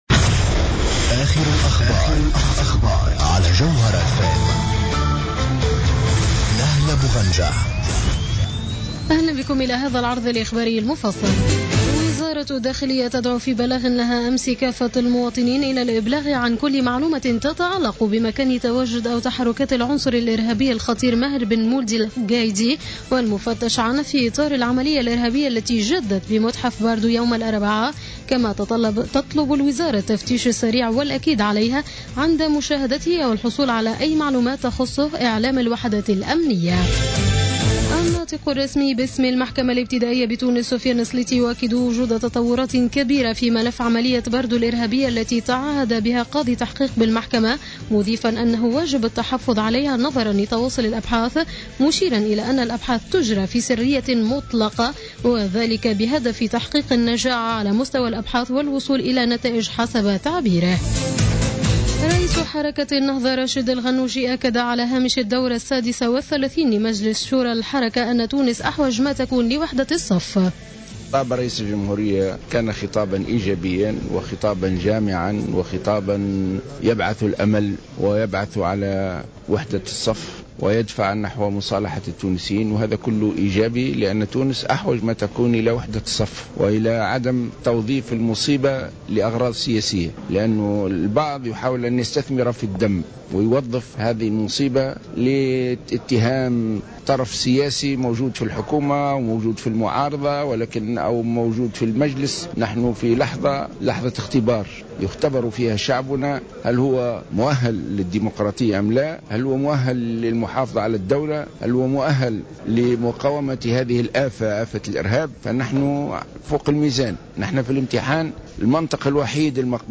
نشرة أخبار منتصف الليل ليوم الأحد 22 مارس 2015